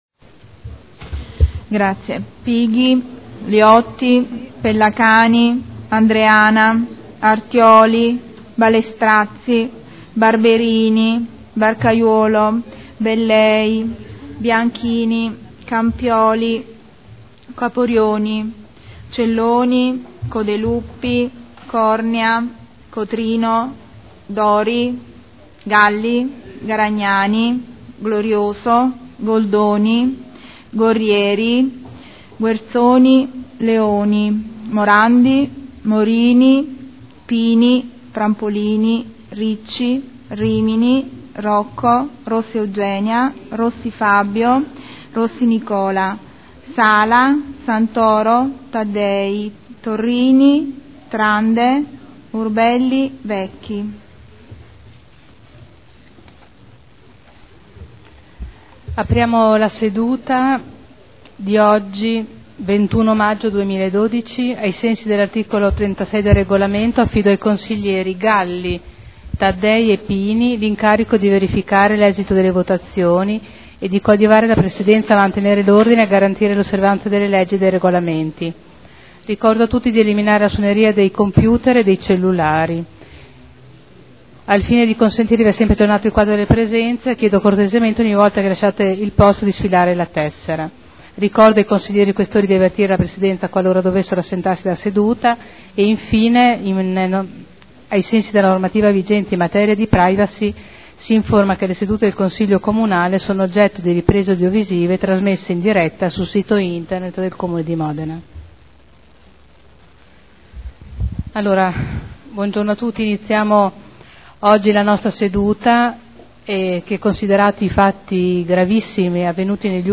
Seduta del 21 maggio Apertura del Consiglio Comunale.